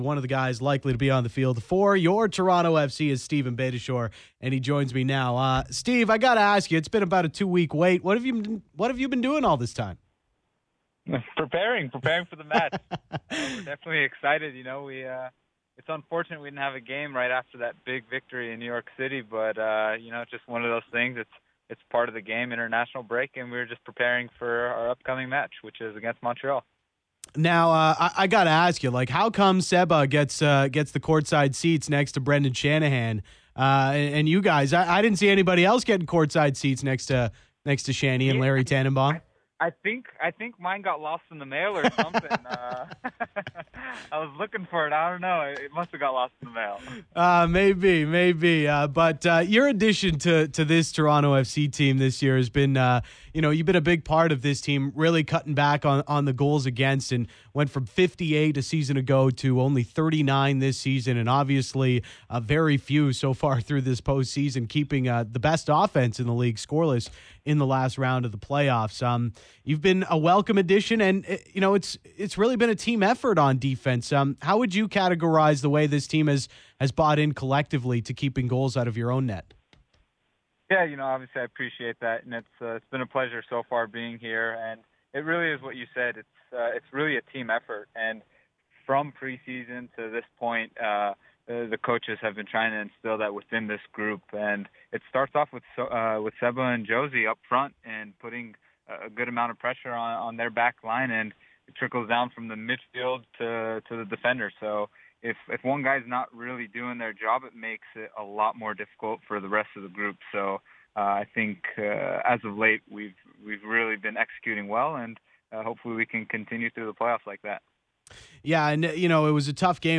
Listen: 1-on-1 with Toronto FC defender Steven Beitashour